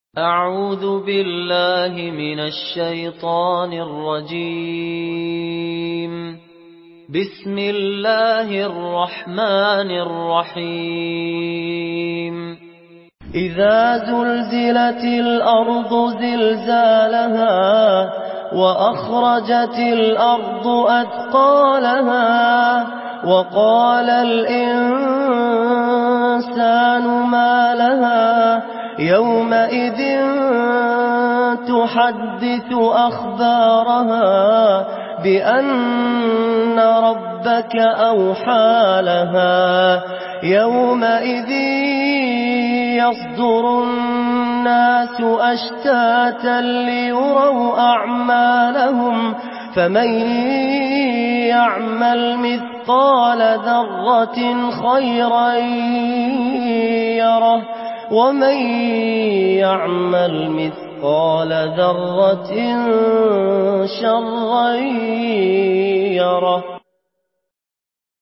سورة الزلزلة MP3 بصوت فهد الكندري برواية حفص
مرتل